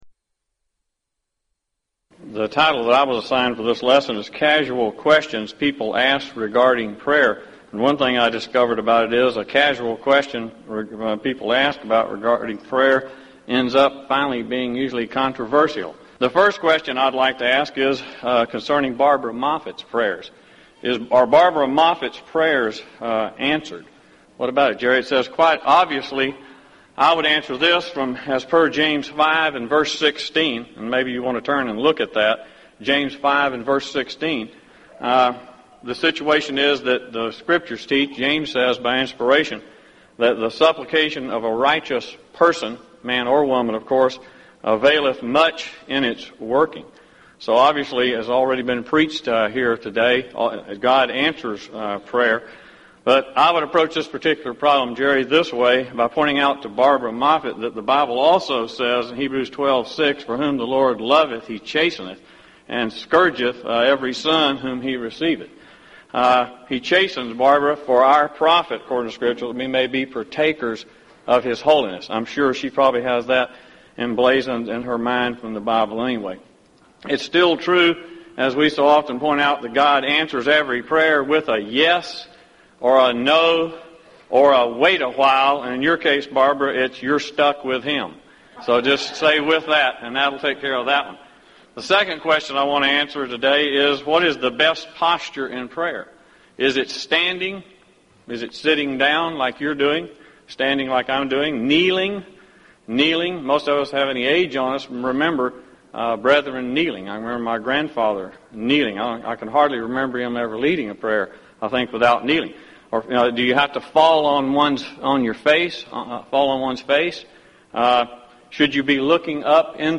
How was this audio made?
Event: 1998 Gulf Coast Lectures